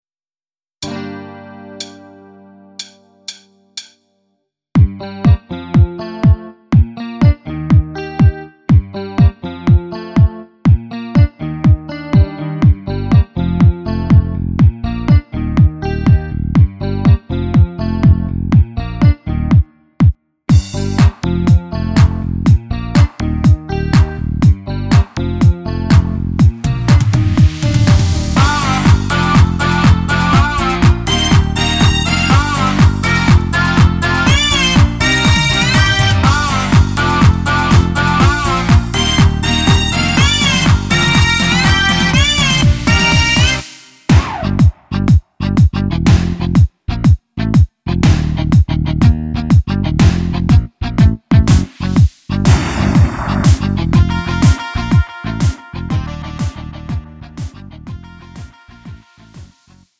음정 원키 3:11
장르 가요 구분